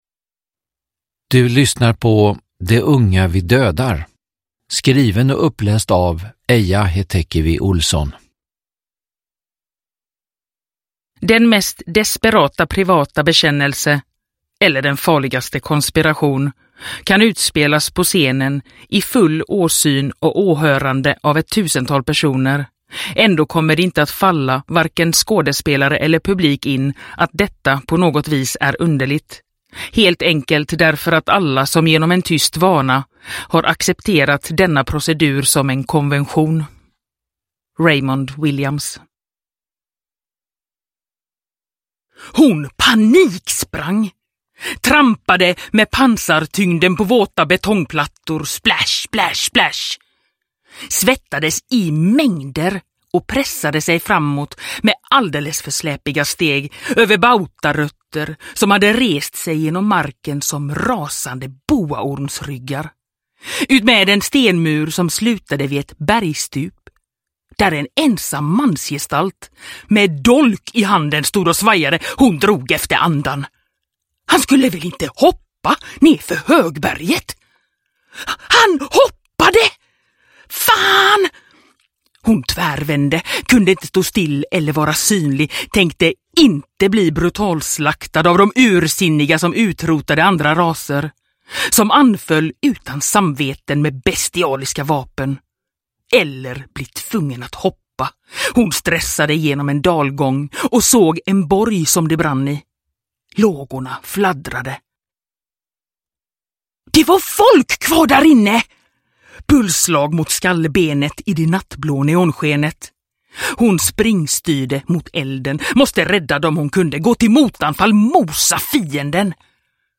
De unga vi dödar – Ljudbok – Laddas ner
Uppläsare: Eija Hetekivi Olsson